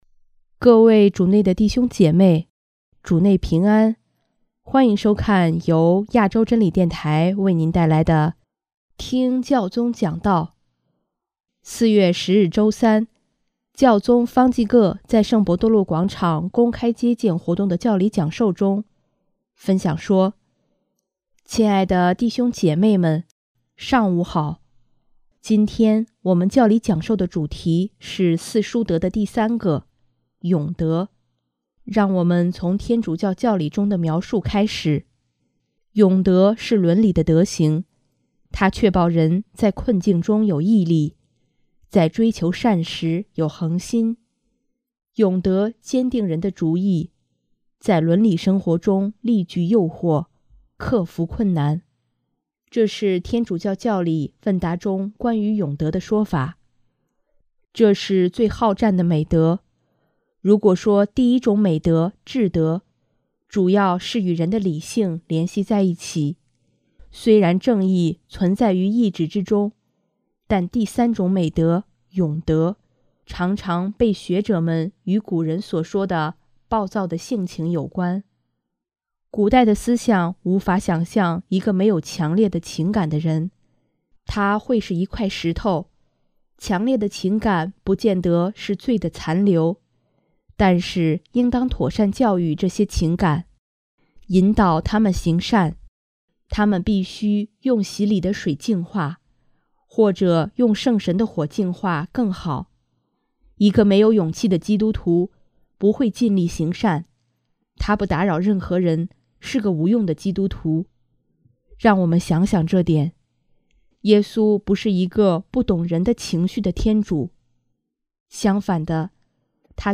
4月10日周三，教宗方济各在圣伯多禄广场公开接见活动的教理讲授中，分享说：